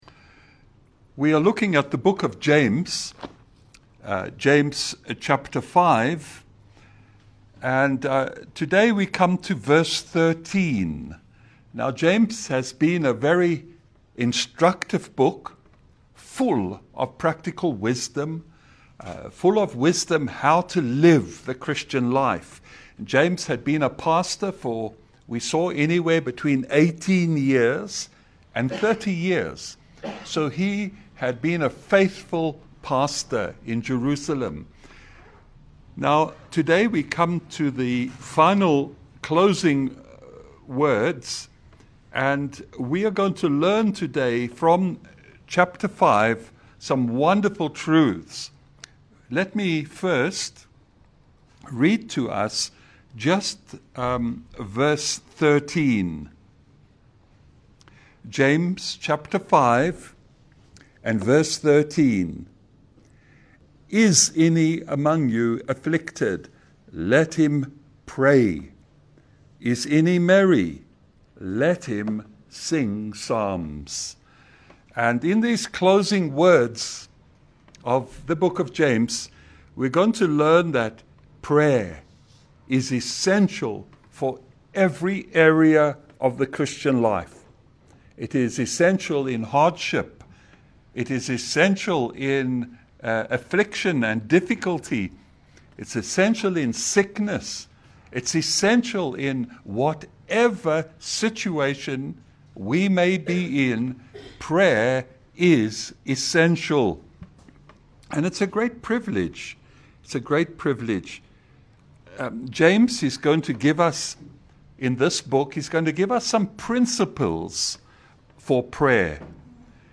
A clear and practical message from James 5 on prayer in suffering, joy, sickness, and spiritual struggle. Learn why constant prayer matters in every part of life.
Service Type: Lunch hour Bible Study